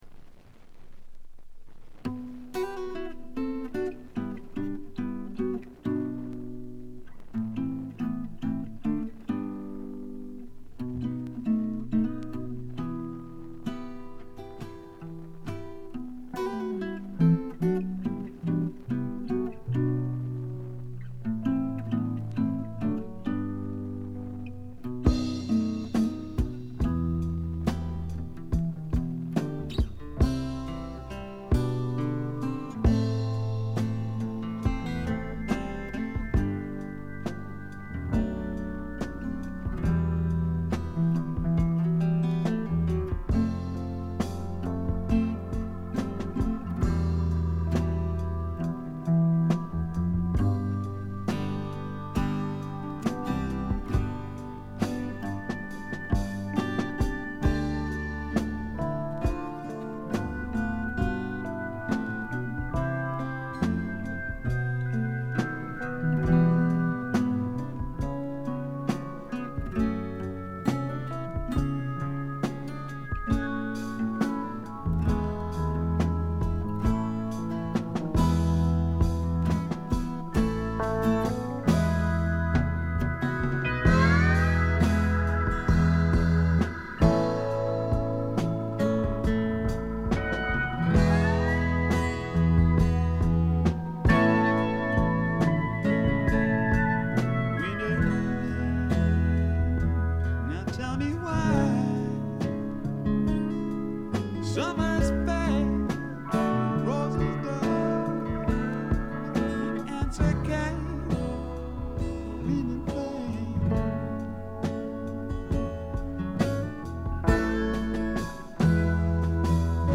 静音部で軽微なバックグラウンドノイズが聴かれる程度。
試聴曲は現品からの取り込み音源です。
※B3a-3b連続です